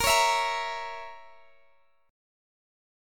Bbdim Chord
Listen to Bbdim strummed